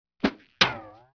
archer_volley1.wav